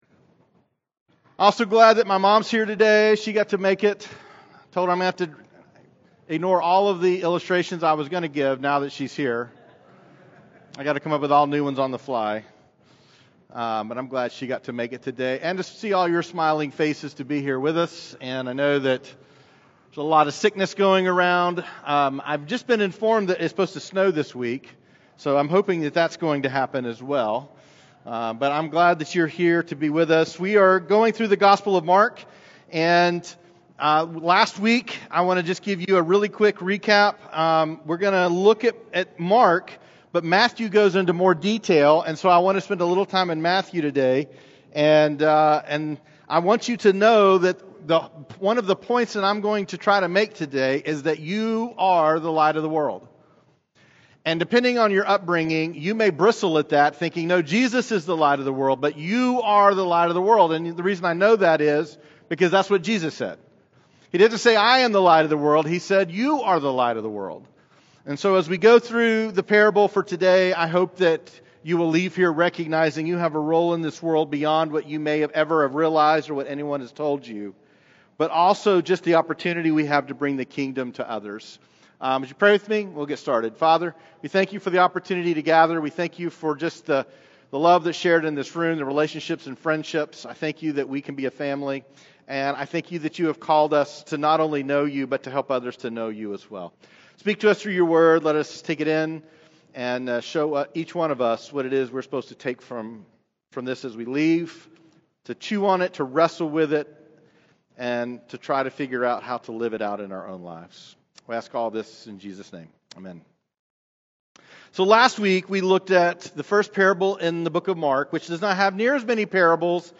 Weekly teaching of Journey Church: A faith community in Chattanooga devoted to making Jesus famous and healing the brokenhearted.